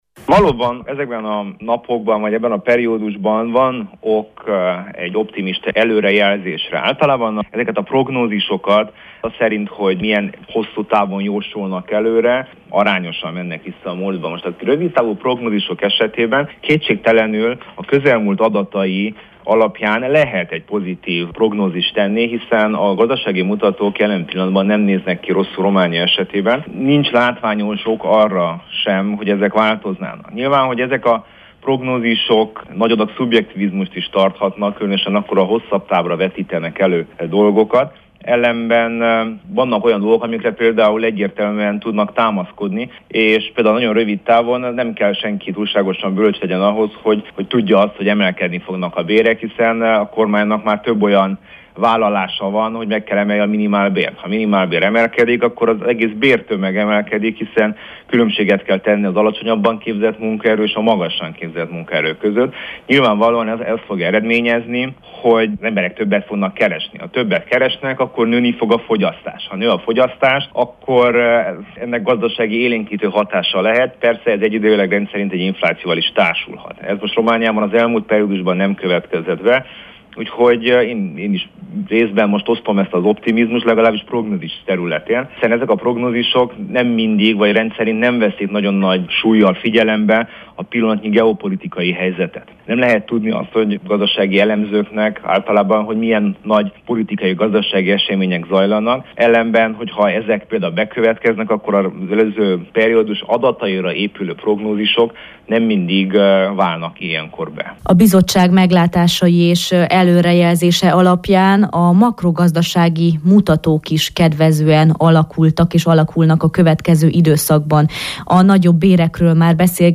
interjújában